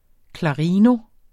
Udtale [ klɑˈʁiːno ]